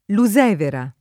Lusevera [ lu @$ vera ]